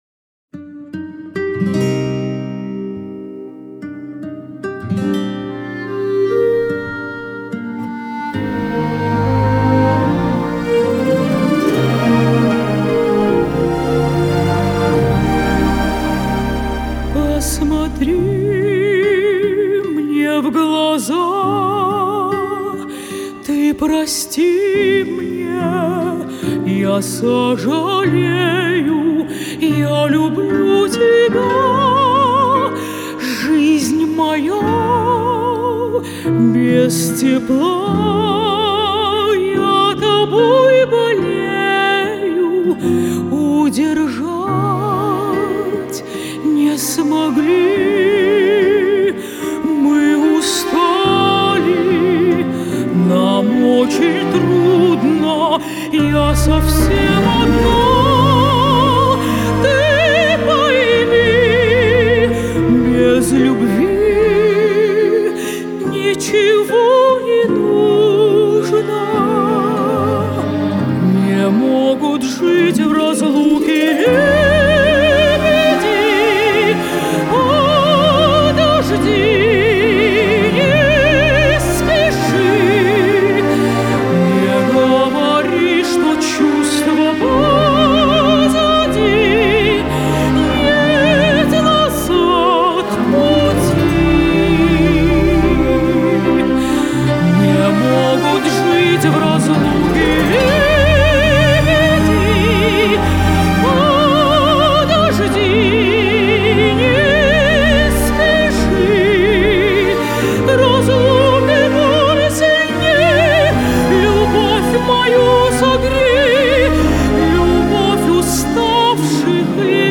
Жанр: Русские песни